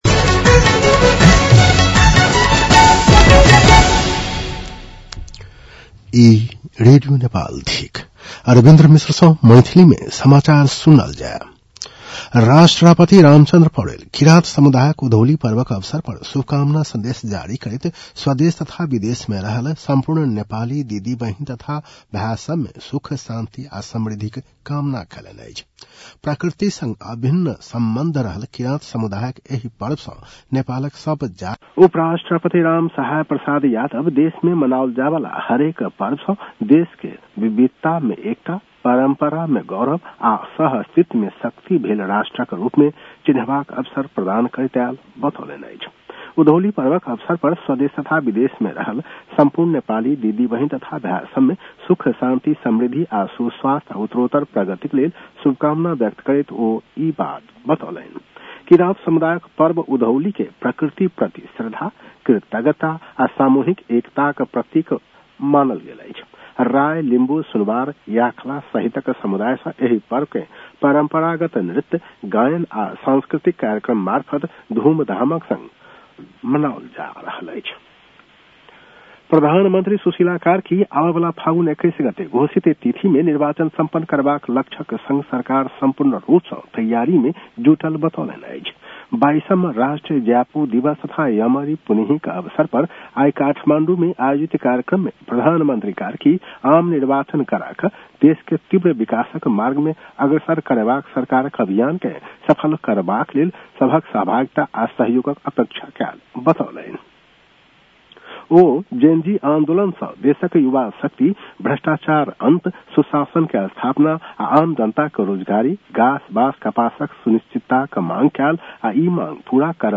मैथिली भाषामा समाचार : १८ मंसिर , २०८२
6.-pm-maithali-news-.mp3